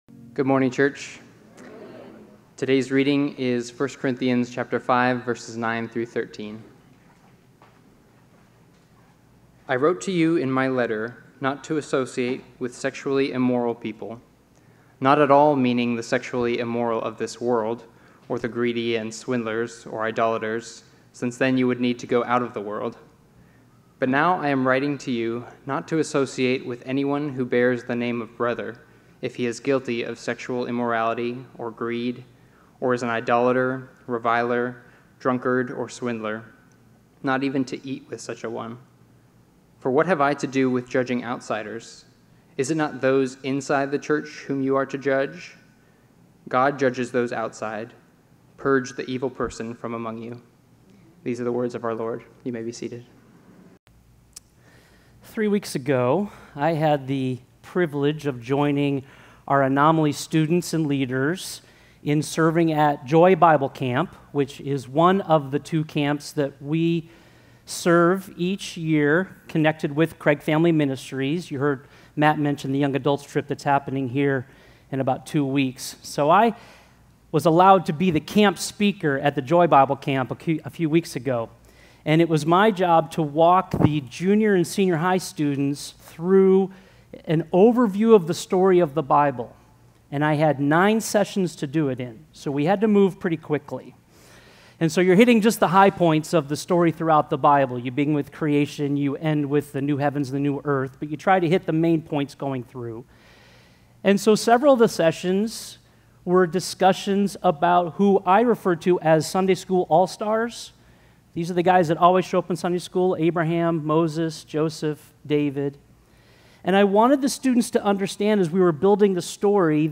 Sermon Notes: Don't Tolerate the Toxic